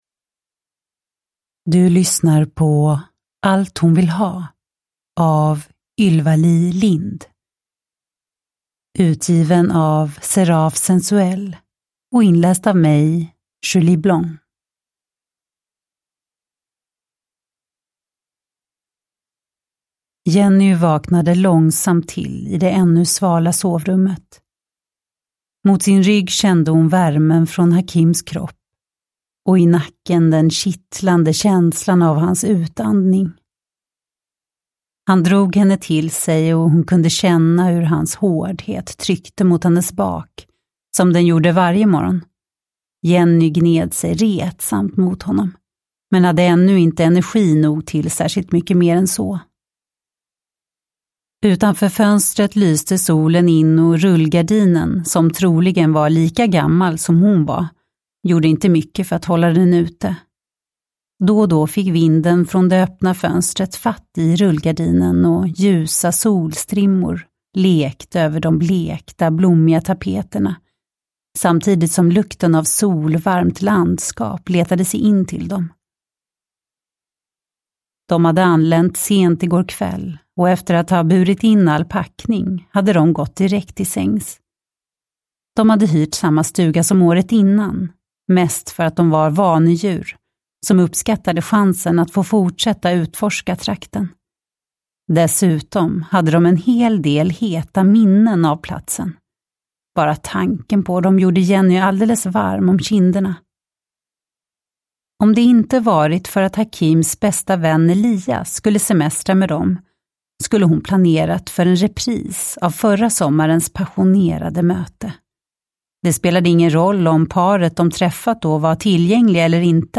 Allt hon vill ha (ljudbok) av Ylva-Li Lindh